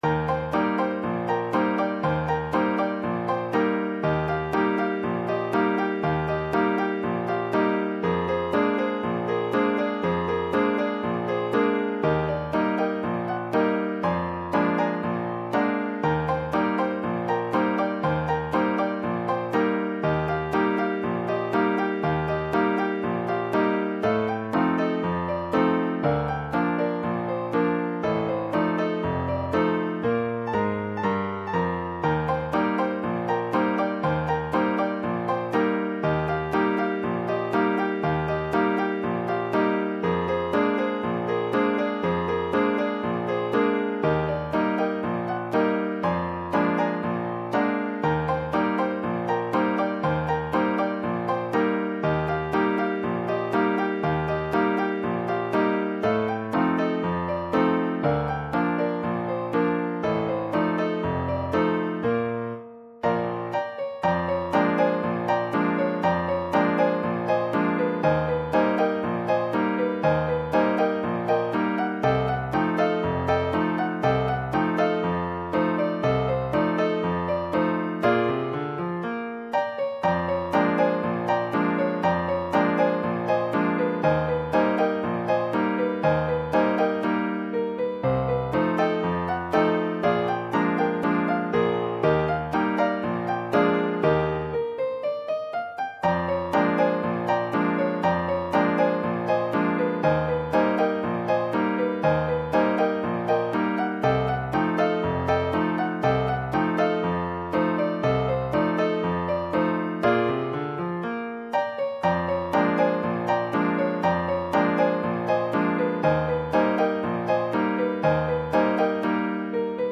This is not a performance version, but is simply a file which lilypond produces when processing the source.
An mpeg (.mp3) file produced from the midi file.